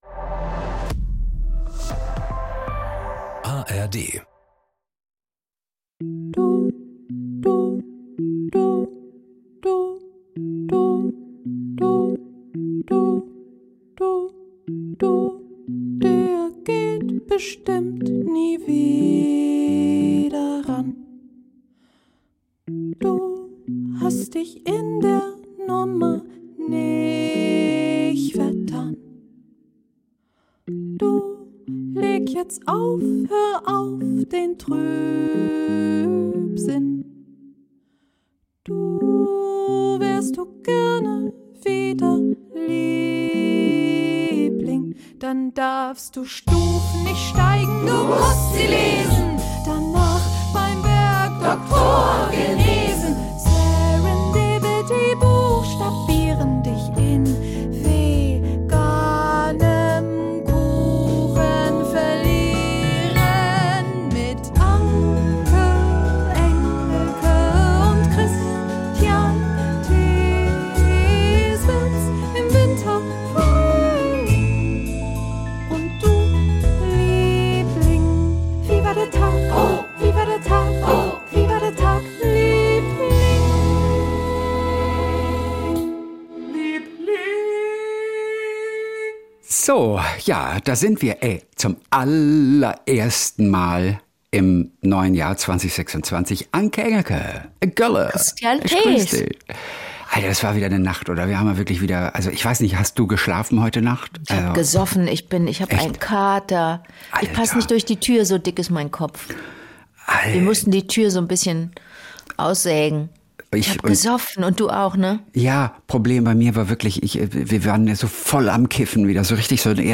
Jeden Montag und Donnerstag Kult: SWR3-Moderator Kristian Thees und seine beste Freundin Anke Engelke erzählen sich gegenseitig ihre kleinen Geschichtchen des Tages.